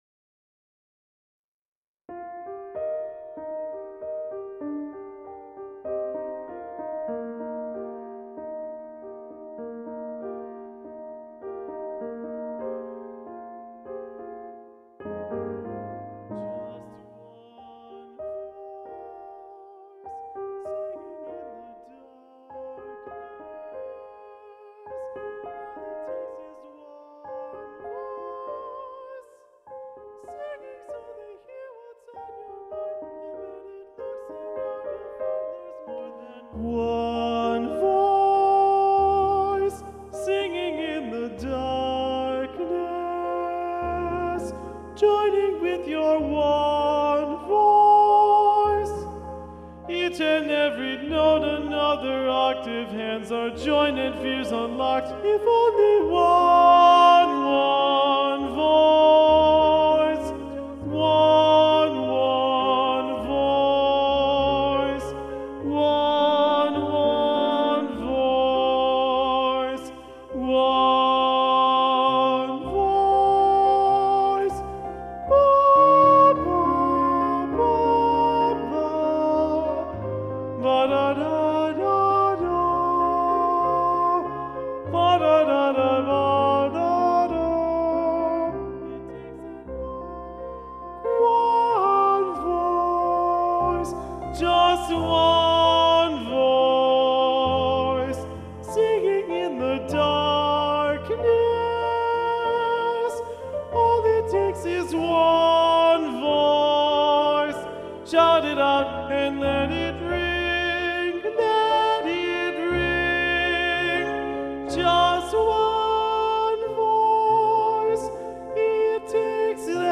ONE VOICE — Part TWO (YOUNG ADULT/ADULT SECTION ONLY)
corrected-part-ii-one-voice-2-part-part-2-predominant-arr.-mark-brymer.mp3